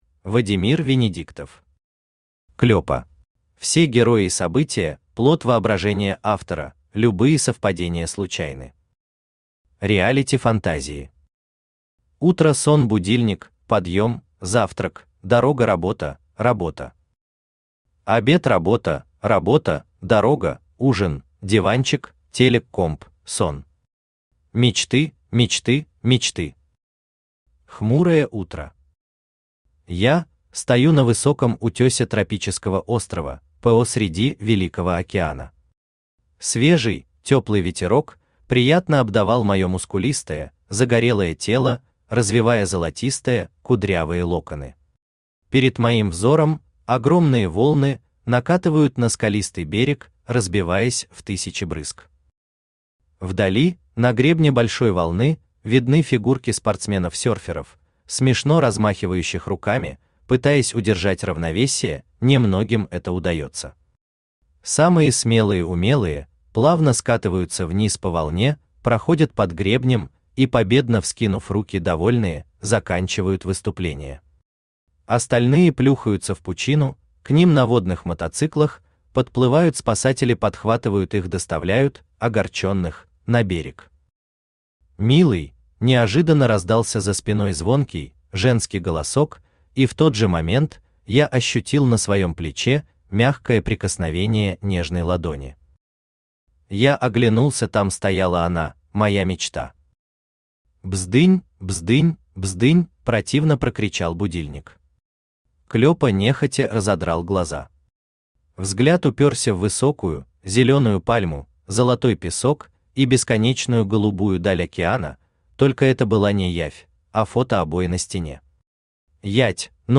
Аудиокнига Клёпа | Библиотека аудиокниг
Aудиокнига Клёпа Автор Вадимир Венидиктов Читает аудиокнигу Авточтец ЛитРес.